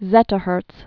(zĕtə-hûrts)